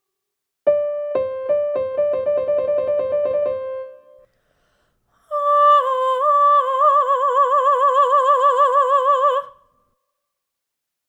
Trills
Flip between the two pitches for a few repetitions, gradually increasing speed.
The key to successfully increasing the speed of the flip between the two pitches is to keep the voice light and agile; eventually, the flipping will turn into a seamless flutter in which both pitches are fused into one continuous sound.